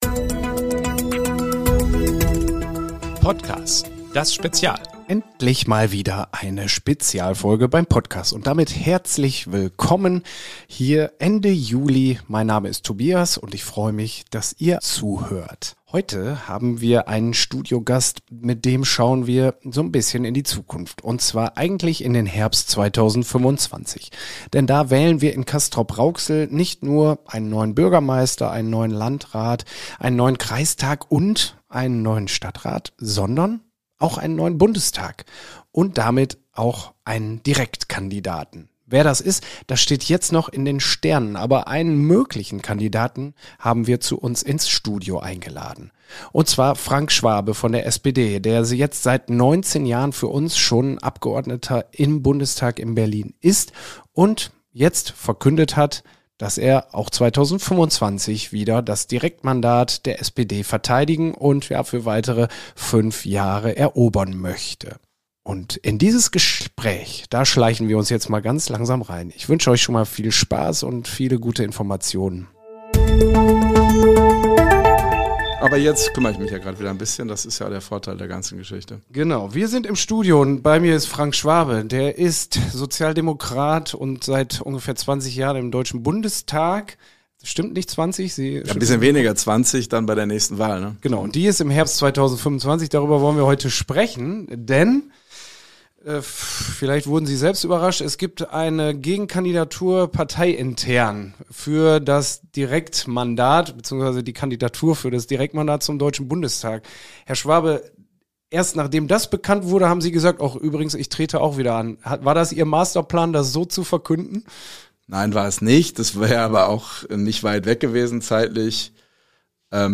Der war bei uns im PottCAS-Studio zu Gast.